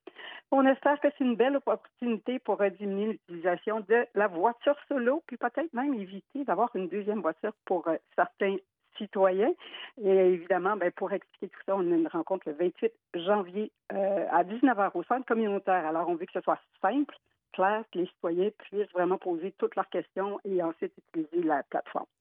Michelle Champagne, mairesse de Bromont.